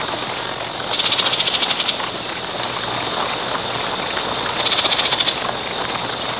Birds And Insects Sound Effect
Download a high-quality birds and insects sound effect.
birds-and-insects-1.mp3